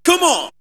VOX SHORTS-2 0003.wav